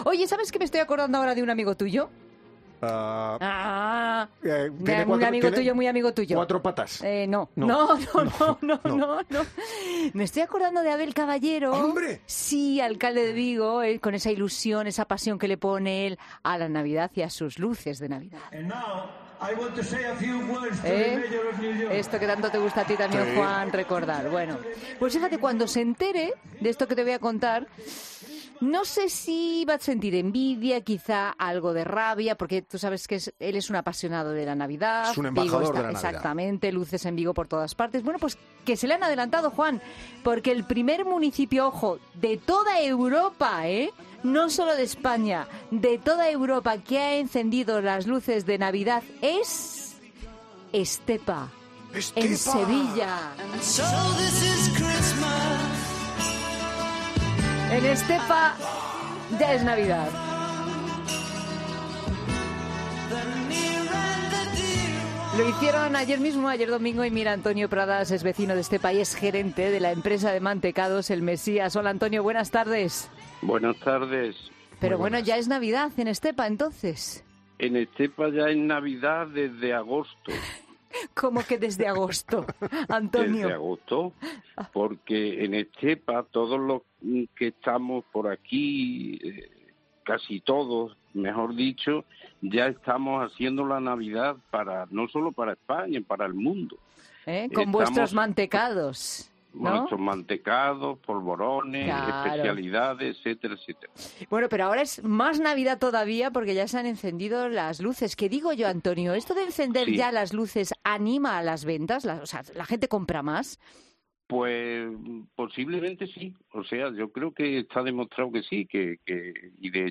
'La Tarde' es un programa presentado por Pilar Cisneros y Fernando de Haro que se emite en COPE, de lunes a viernes, de 15 a 19 horas.